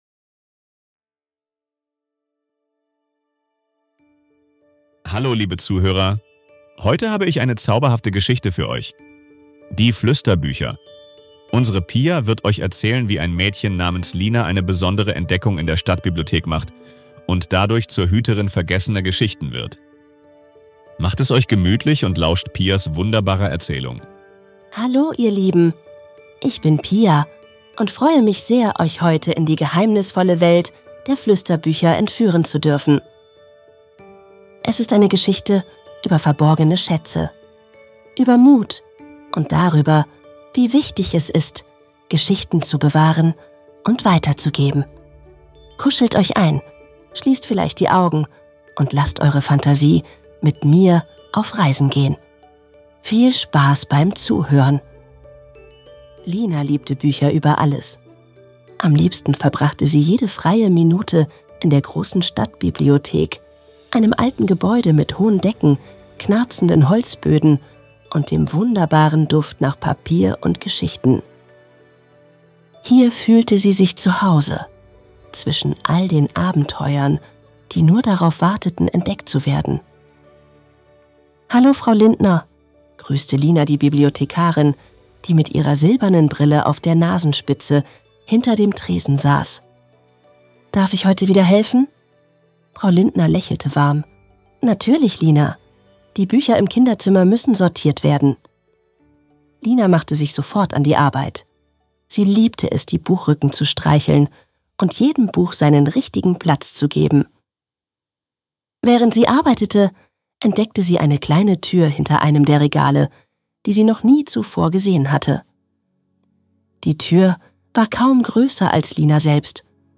Geschichten-Die-Fluesterbuecher.mp3